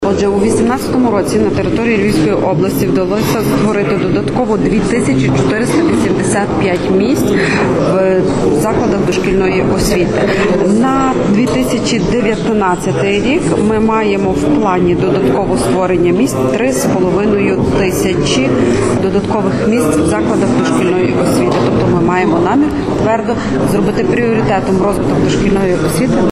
Про це повідомила директор департаменту освіти і науки Львівської ОДА Любомира Мандзій.